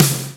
SNARE 109.wav